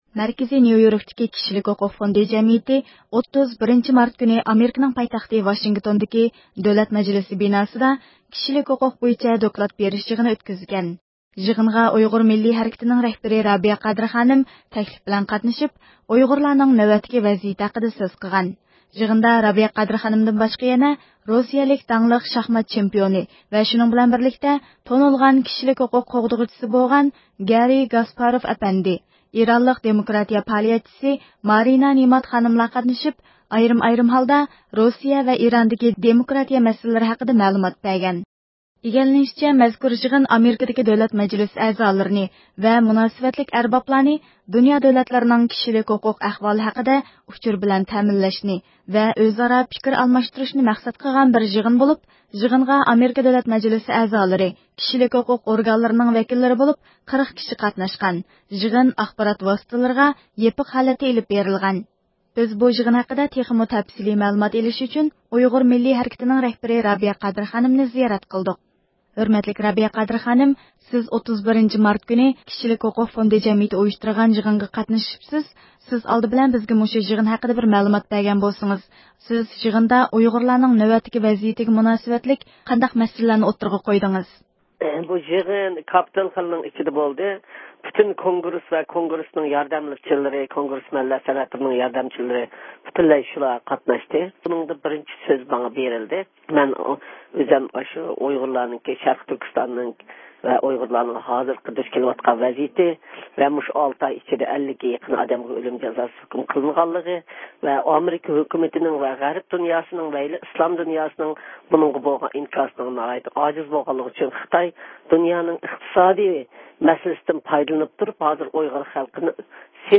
بىز بۇ يىغىن ھەققىدە تېخىمۇ تەپسىلىي مەلۇمات بېرىش ئۈچۈن ئۇيغۇر مىللىي ھەرىكىتىنىڭ رەھبىرى رابىيە قادىر خانىمنى زىيارەت قىلدۇق.